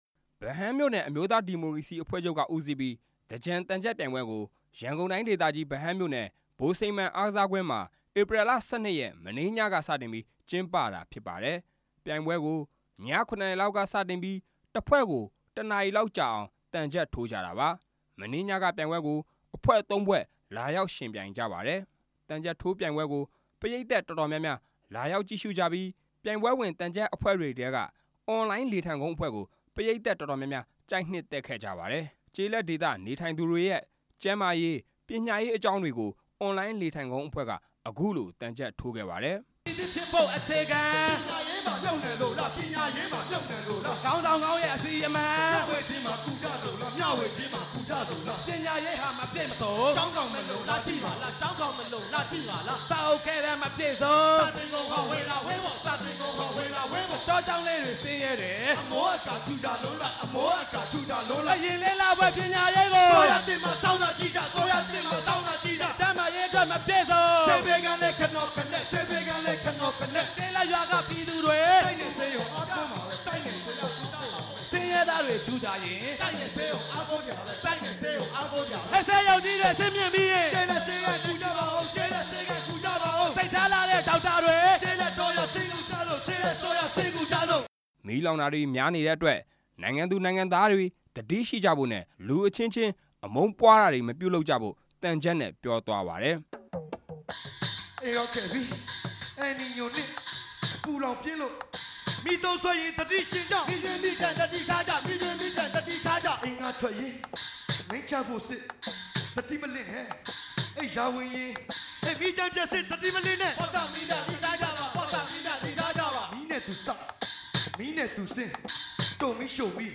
ရန်ကုန်တိုင်းဒေသကြီး ဗဟန်းမြို့နယ်က ဗိုလ်စိန်မှန်အားကစားကွင်းမှာ သြင်္ကန်အကြို နေ့ကနေ အတက်နေ့ထိ သံချပ်ပြိုင်ပွဲကျင်းပနေပါတယ်။ သြင်္ကန်အကြိုနေ့ မနေ့ညက သံချပ်အဖွဲ့ ၃ဖွဲ့ ဝင်ရောက်ယှဉ်ပြိုင်ခဲ့ပါတယ်။